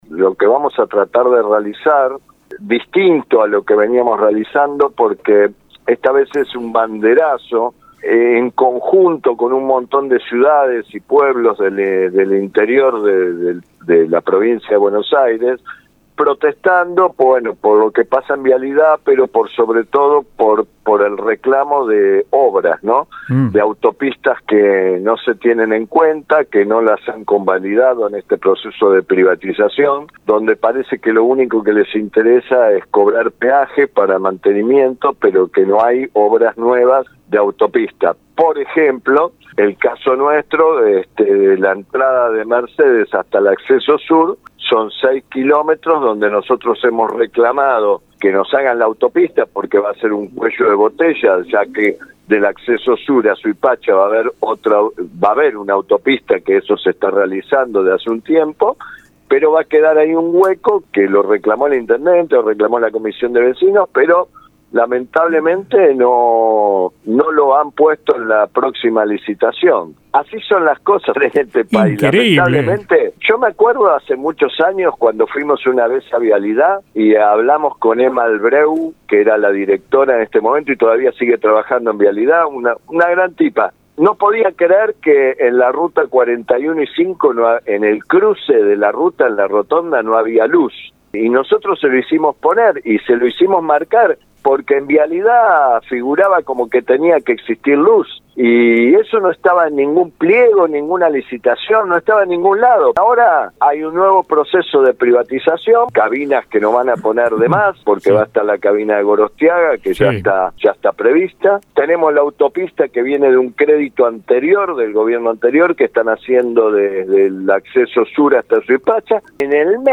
EN RADIO UNIVERSO 93 1